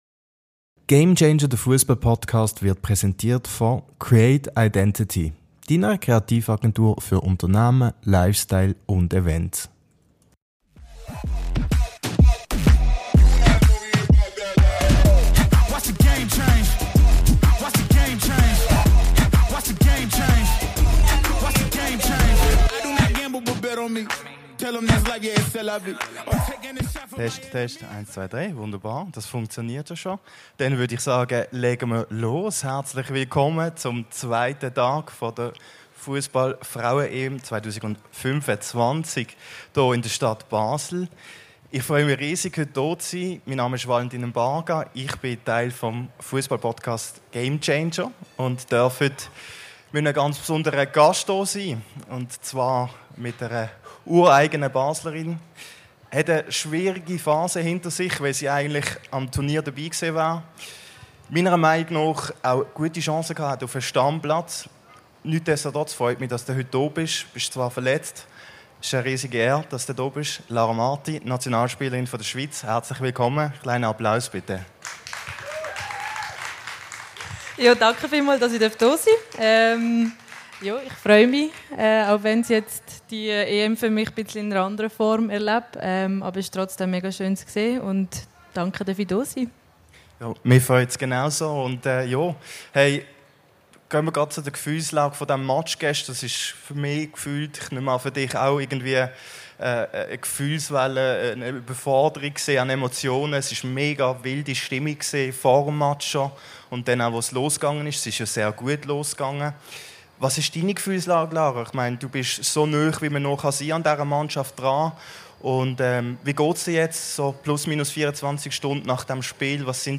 Eine Folge zwischen persönlicher Tiefe und fachlicher Analyse live aufgezeichnet im Herzen der Host City Basel.